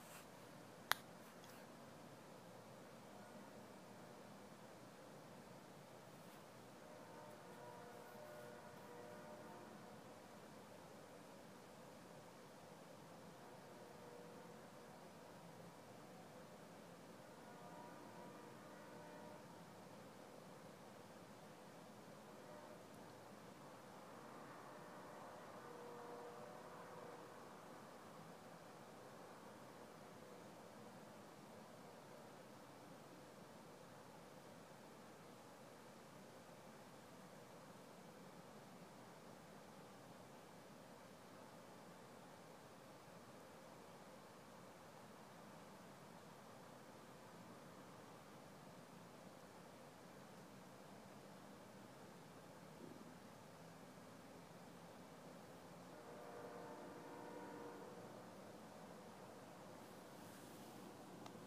I’ve gathered 50 or 60 clips today, each a minute long.